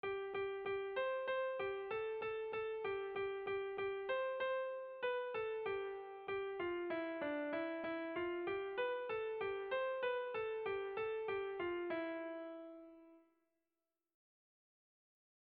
Erromantzea
Zegama < Goierri < Gipuzkoa < Basque Country
Seiko handia (hg) / Hiru puntuko handia (ip)